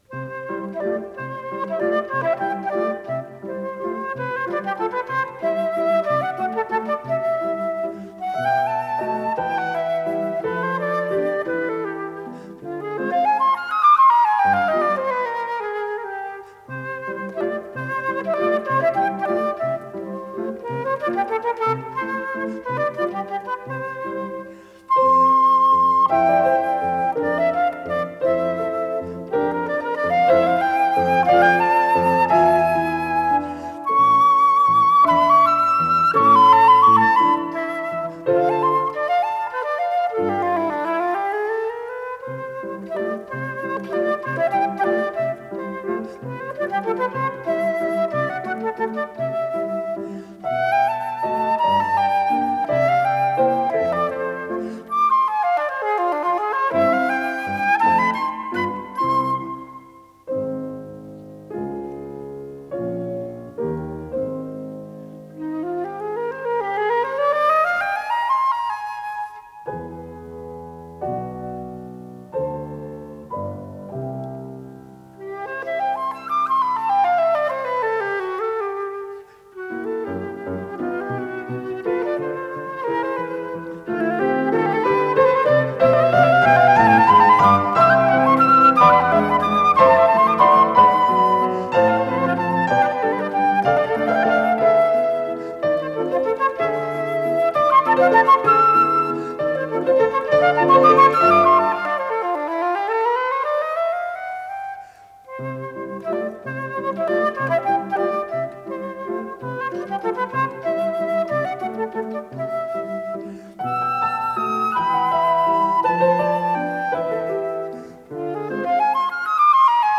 с профессиональной магнитной ленты
флейта
Скорость ленты38 см/с